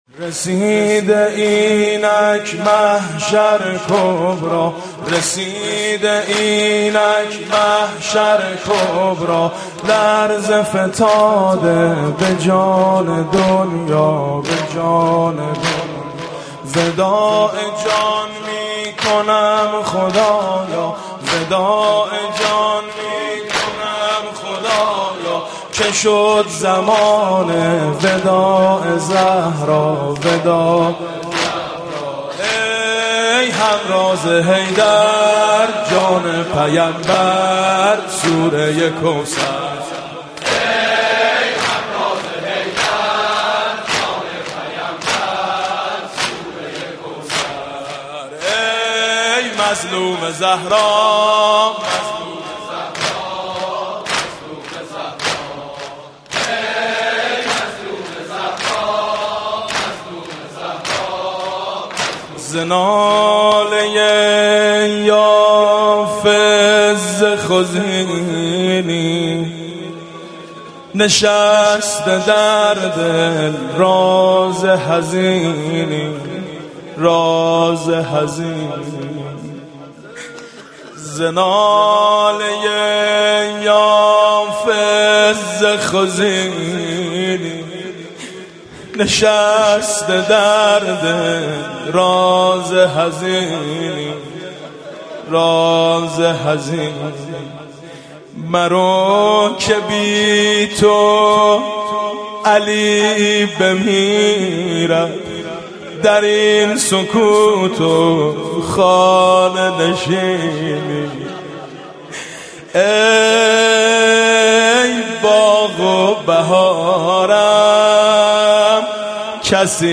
دهه اول فاطمیه اسفند 1392
هیئت شهدای گمنام تهران